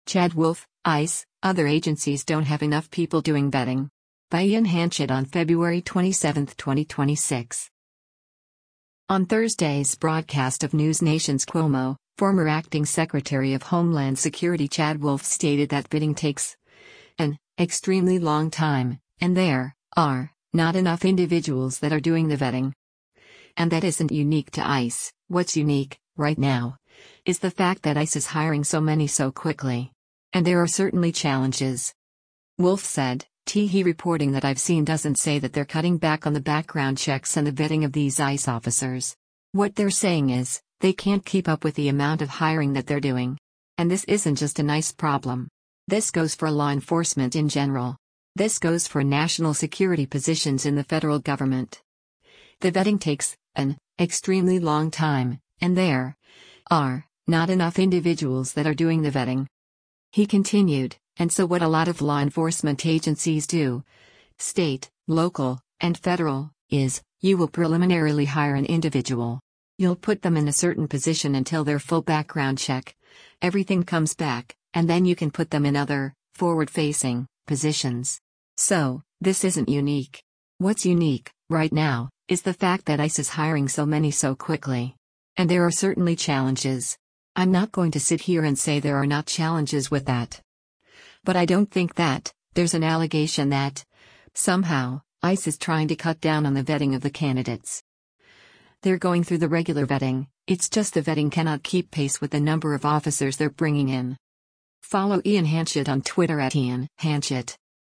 On Thursday’s broadcast of NewsNation’s “Cuomo,” former acting Secretary of Homeland Security Chad Wolf stated that “vetting takes [an] extremely long time, and there [are] not enough individuals that are doing the vetting.” And that isn’t unique to ICE, “What’s unique, right now, is the fact that ICE is hiring so many so quickly.